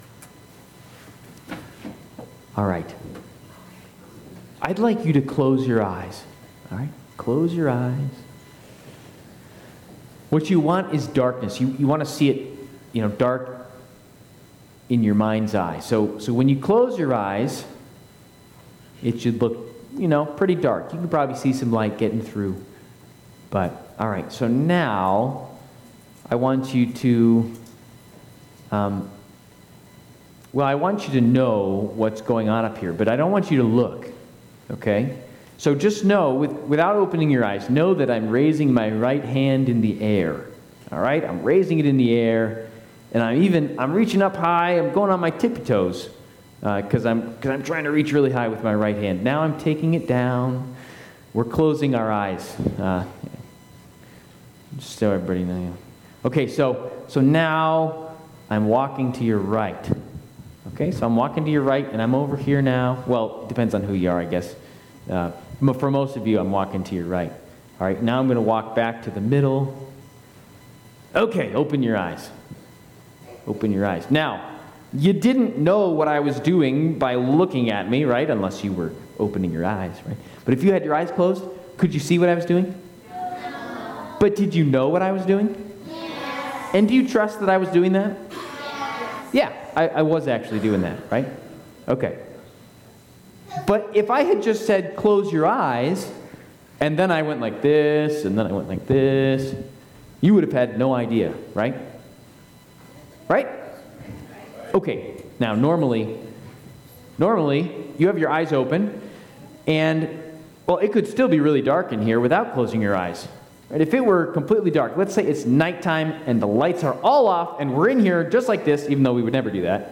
Advent Mid-week Service&nbsp